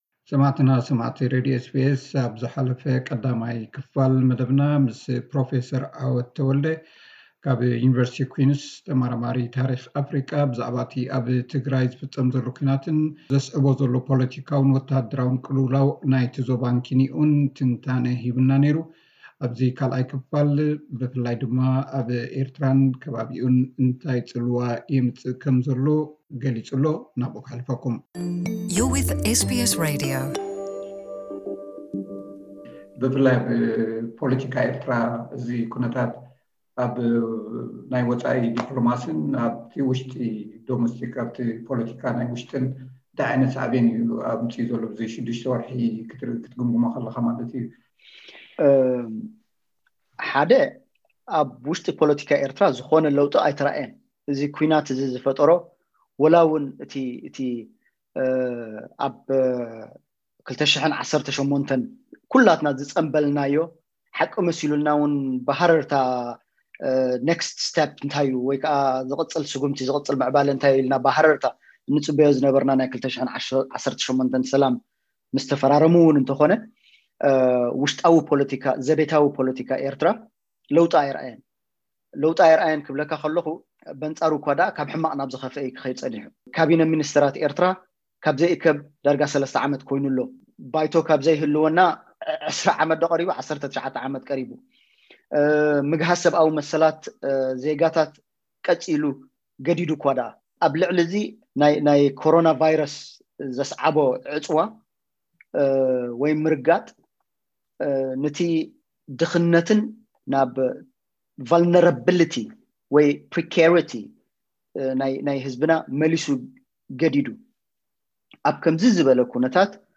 ካልኣይ ክፋል ቃለ መሕትት ምስ ፕሮ